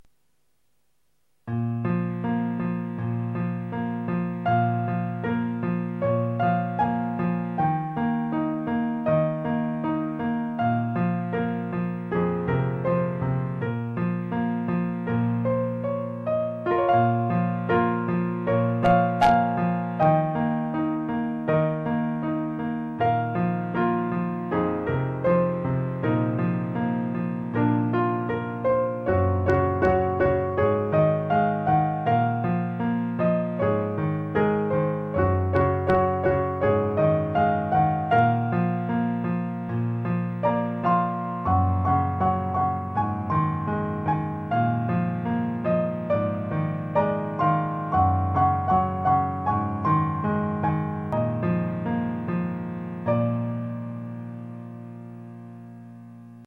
ピアノバージョンです。